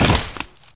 hit_wood2.ogg